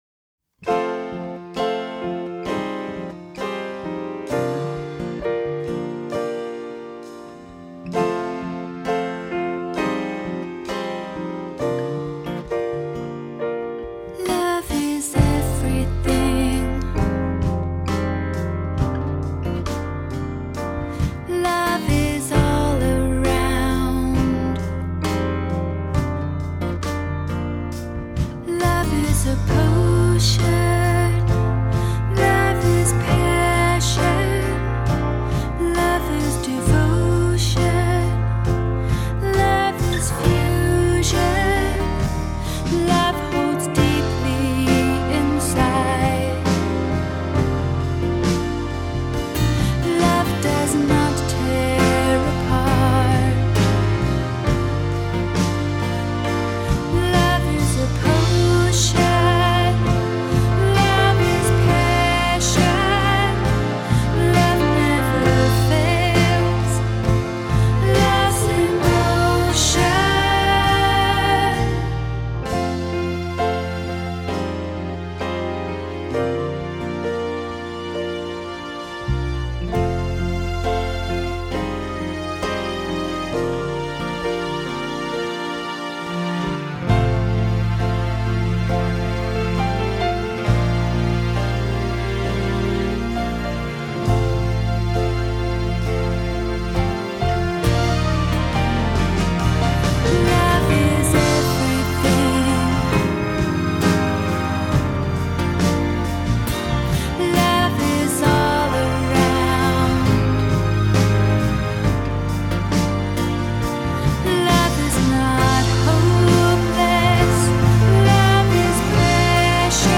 piano, vocals, keyboard
bass guitar
acoustic guitar
drums